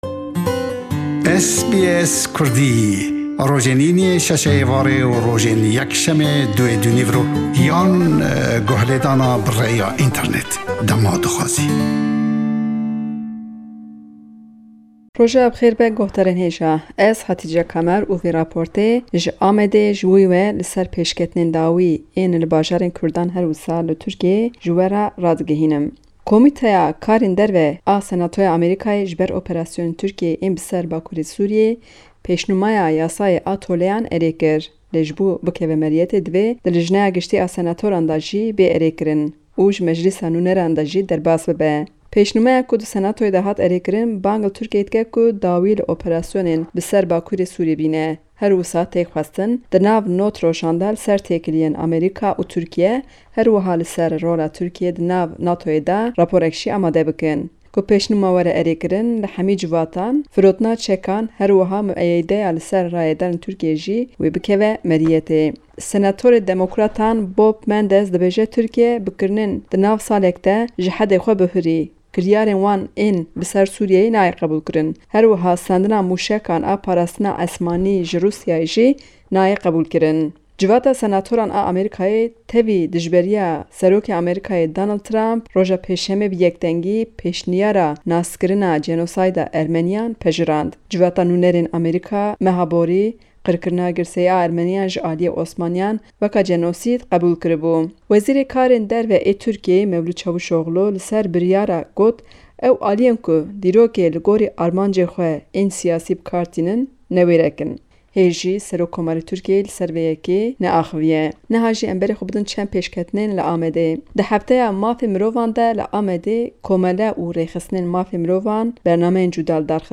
Raporta
hevpeyvineke kurt